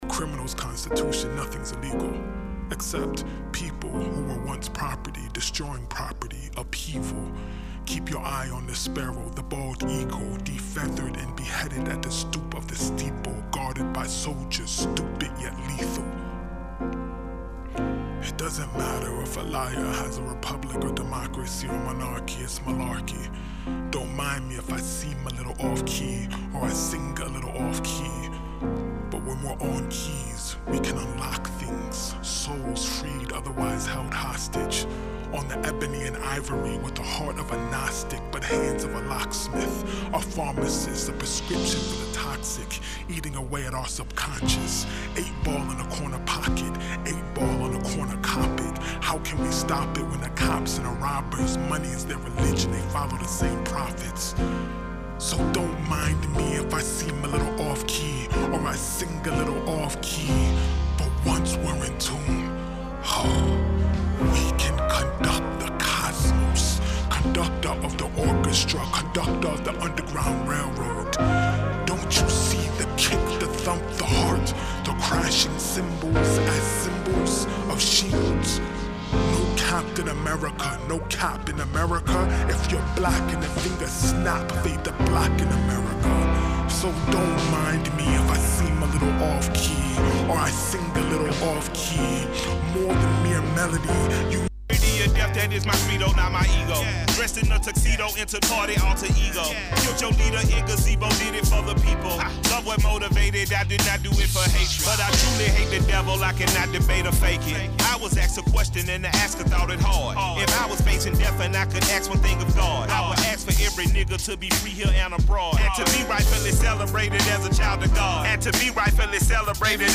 ⌂ > Vinyly > Hiphop-Breakbeat >